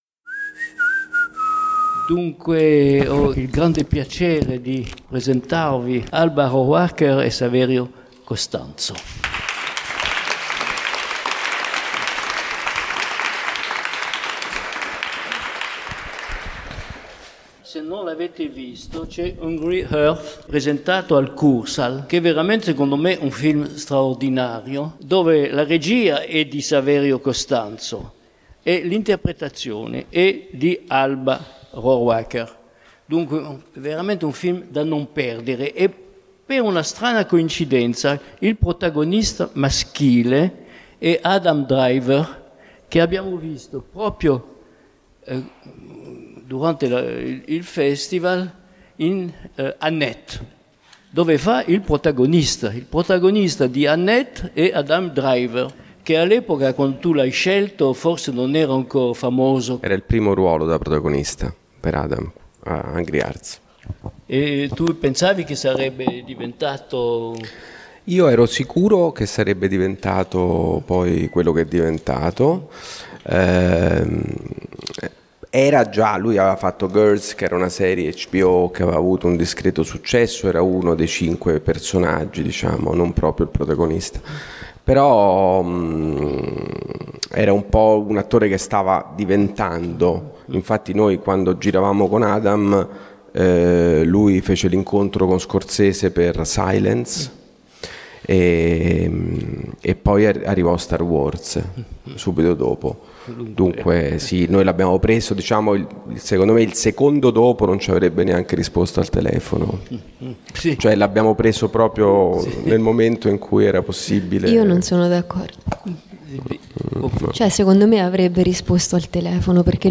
Alba Rohrwacher e Saverio Costanzo dialogano con Jean Gili
Dal cinema alla serie TV l'attrice e il regista si raccontano al bifest 2021.